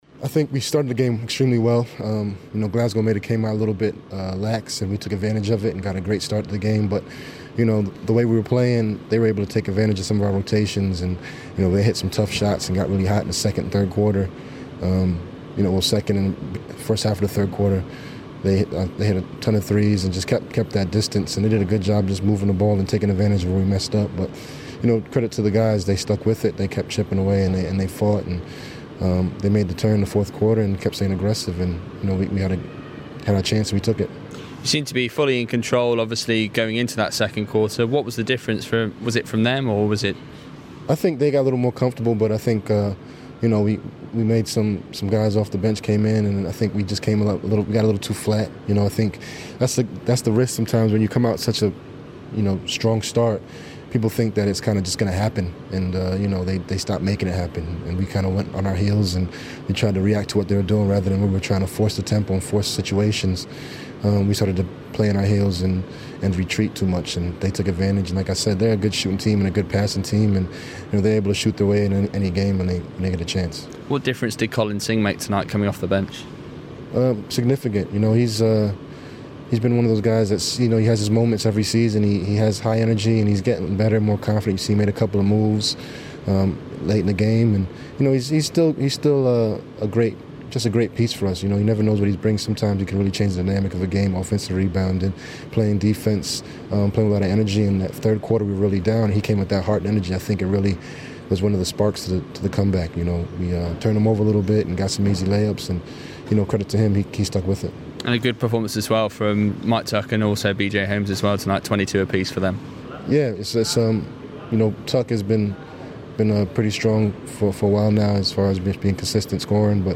Speaking to BBC Radio Sheffield